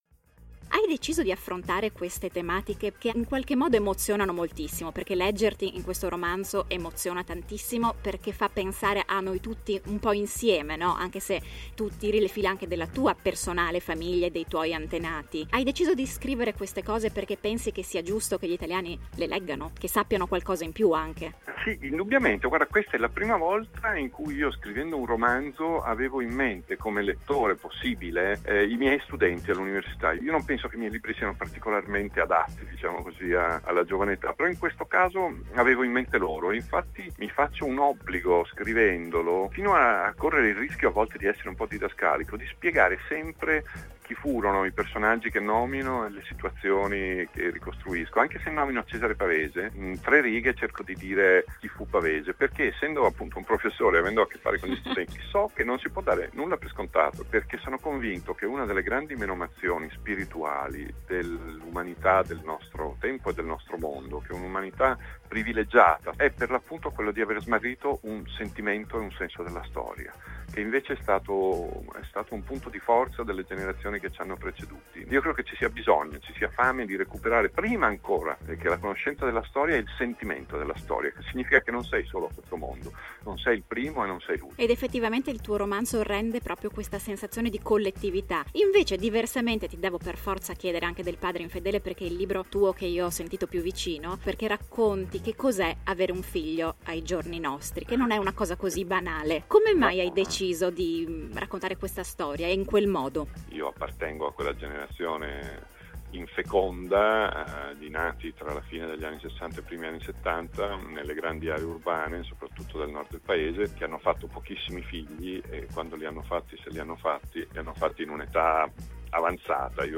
Il tempo migliore della nostra vita, intervista ad Antonio Scurati